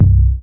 BAS_DncBa14.wav